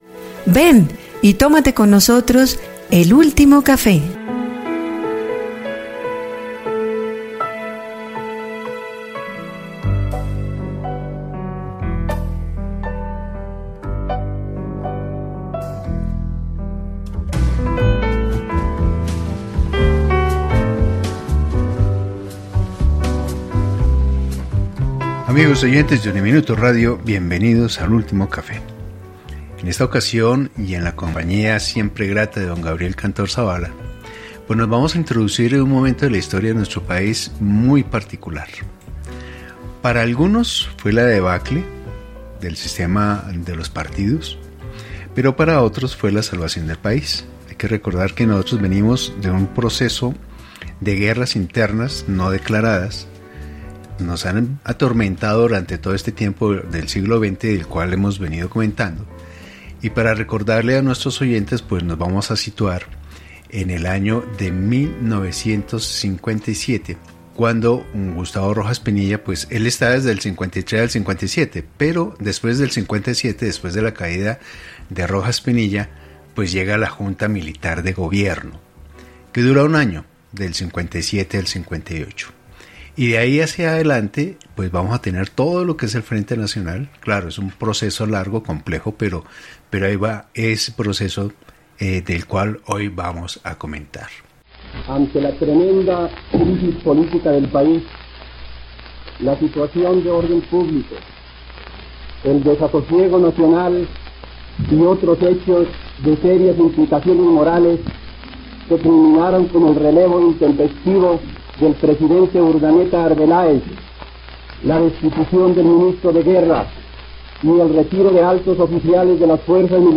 Casi medio siglo de acuerdos políticos, pero pocas realizaciones para establecer la paz, la equidad y un desarrollo equilibrado para hacer de nuestro país una nación. Conversación